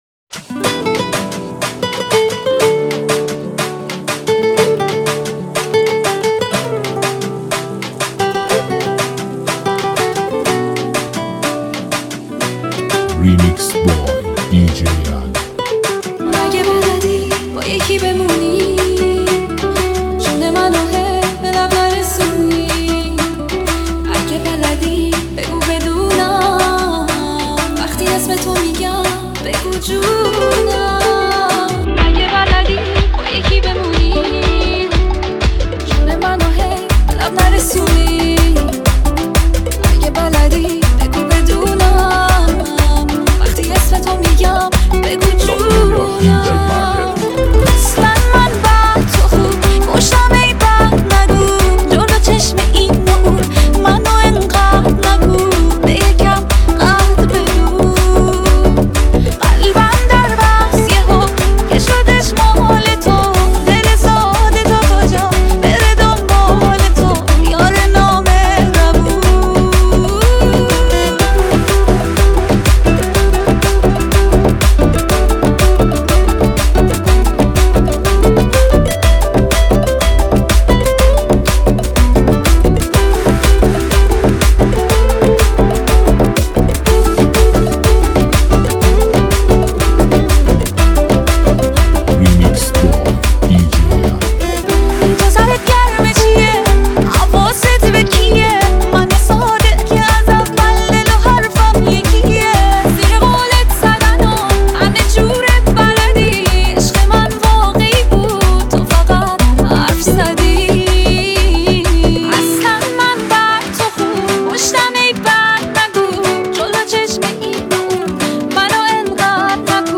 موسیقی شنیدنی و پرانرژی برای لحظاتی پر از احساس و شادی.